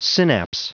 Prononciation du mot synapse en anglais (fichier audio)
Prononciation du mot : synapse